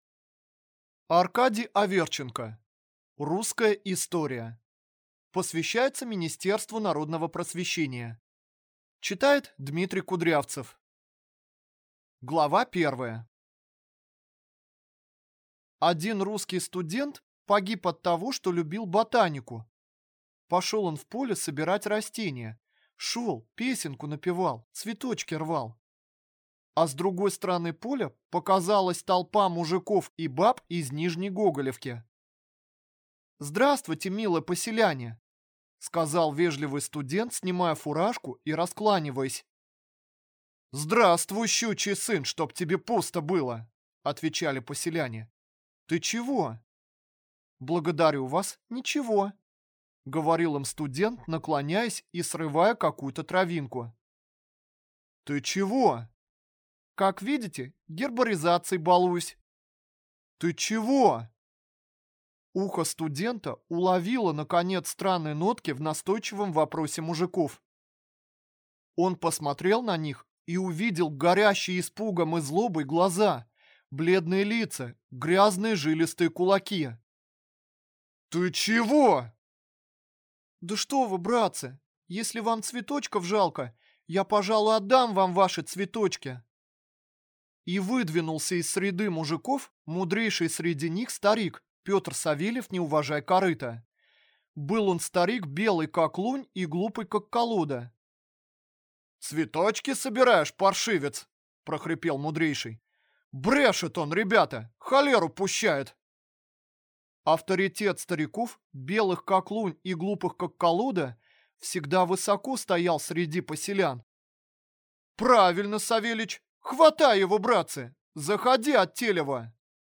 Аудиокнига Русская история | Библиотека аудиокниг